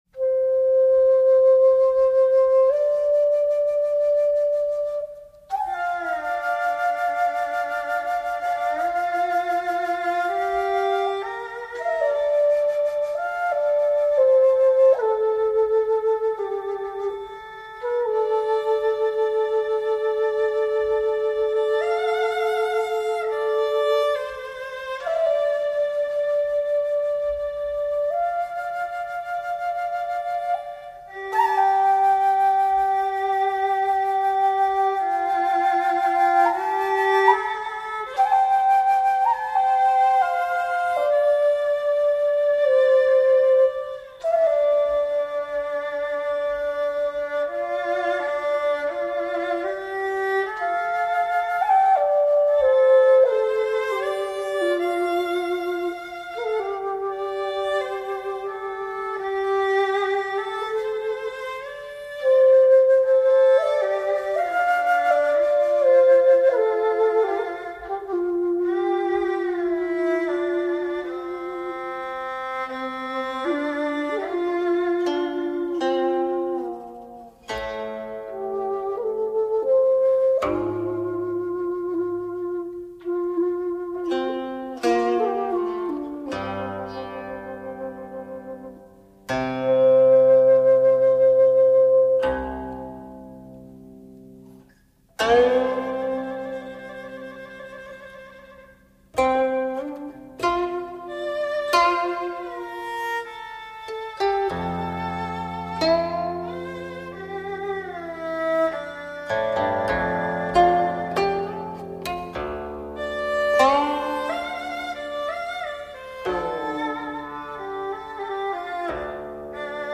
音乐类型：民乐
Qin,Xiao and Erhu
古琴、箫、二胡三重奏